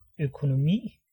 Ääntäminen
Vaihtoehtoiset kirjoitusmuodot (vanhahtava) œconomy Ääntäminen US RP : IPA : /iˈkon.ə.mi/ US : IPA : /iˈkɒːn.ə.miː/ Lyhenteet ja supistumat (laki) Econ.